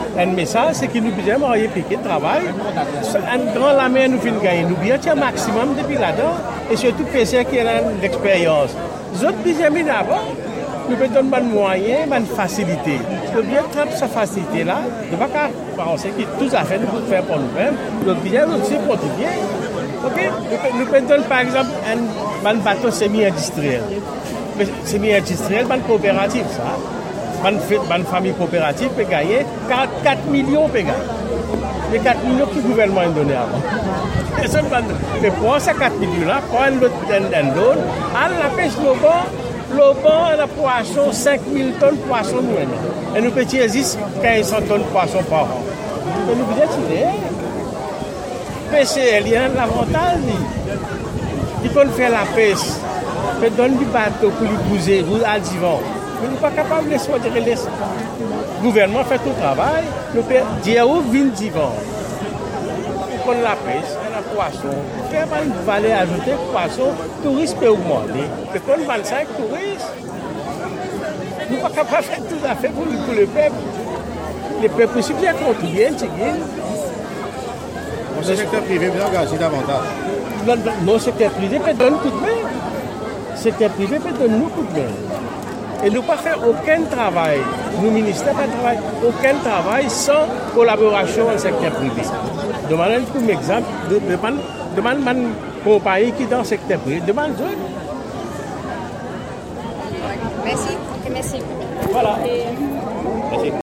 Il intervenait ce dimanche 8 juillet, à l’issue de la messe dite dans le cadre de la Journée mondiale de Prière pour les gens de la Mer, à l’église St Malo, à Baie-du-Tombeau.